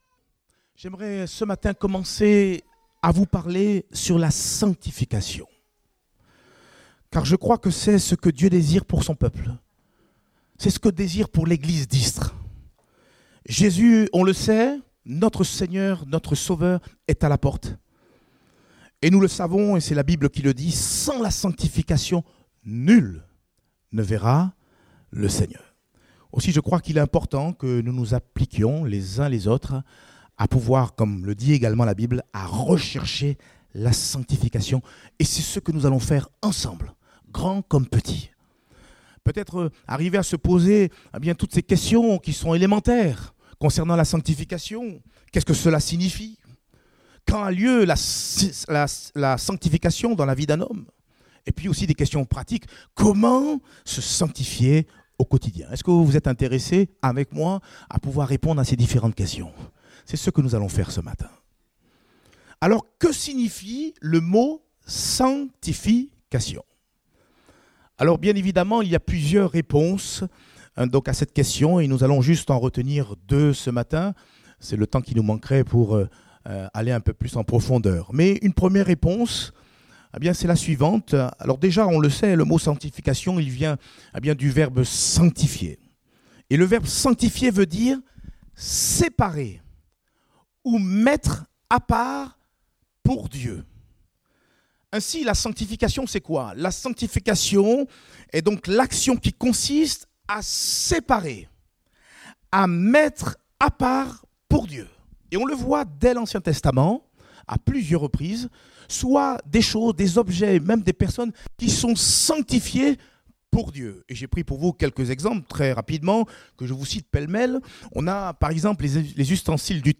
Date : 13 janvier 2019 (Culte Dominical)